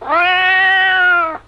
meow4.wav